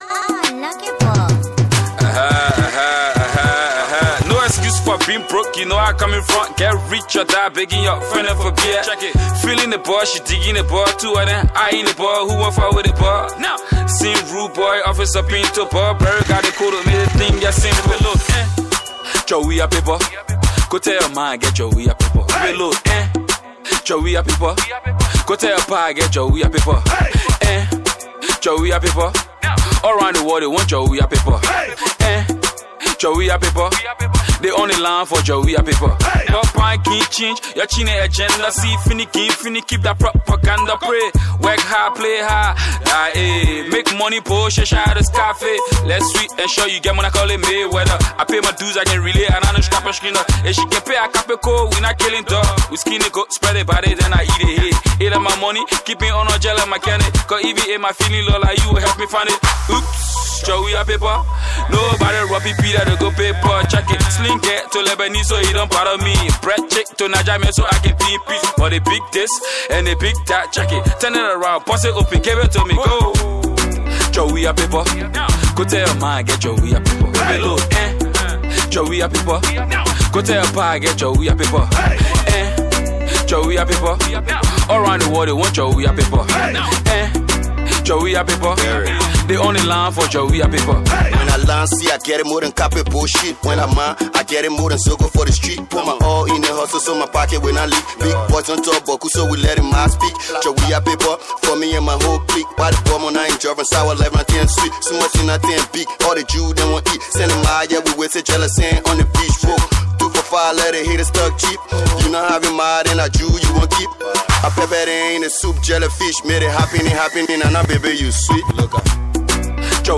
/ Afrobeats/Afro-Pop, Colloquial, Hip-Co, Hip-Hop / By
new and hot hipco vibe